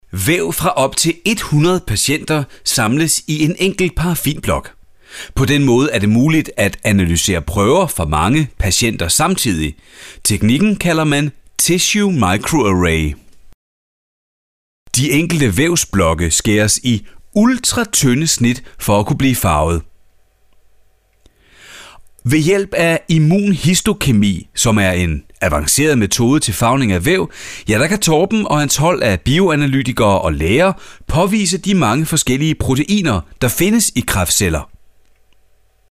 I am a male speaker living in Denmark.
Sprechprobe: eLearning (Muttersprache):
Danish voice over talent.